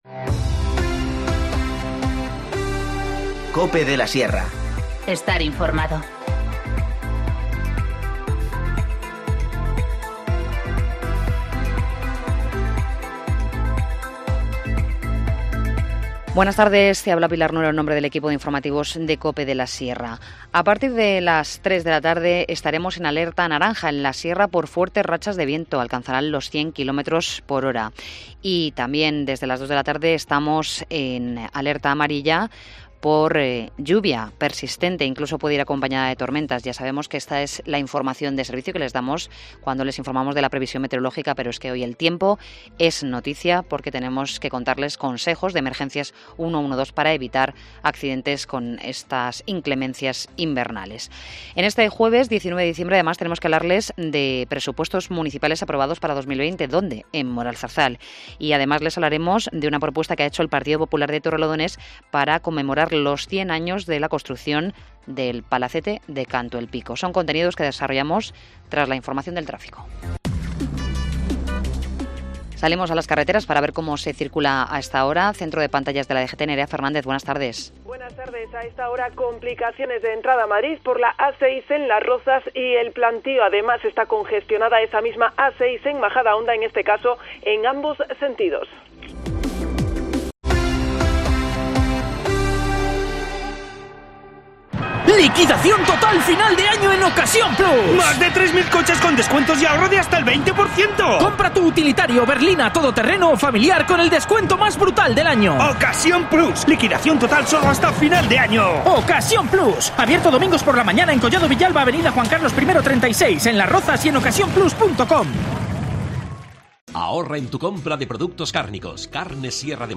Informativo Mediodía 19 diciembre 14:20h